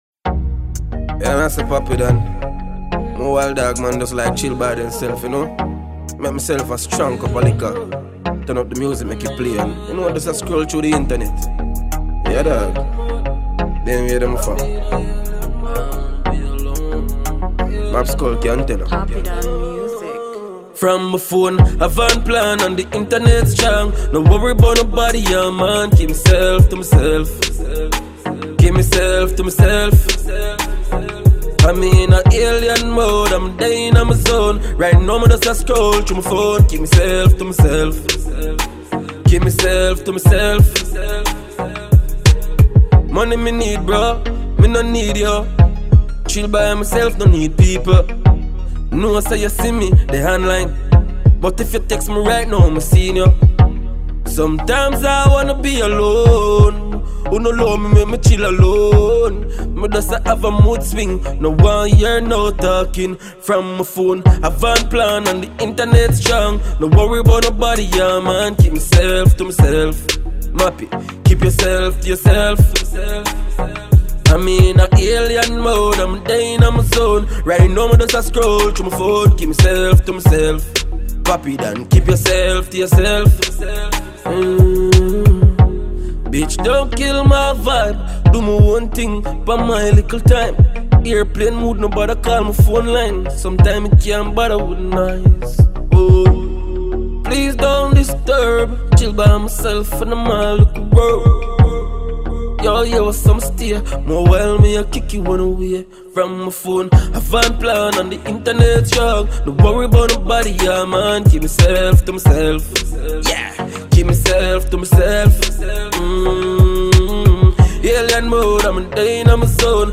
We present to you this 2020 tune from Dancehall star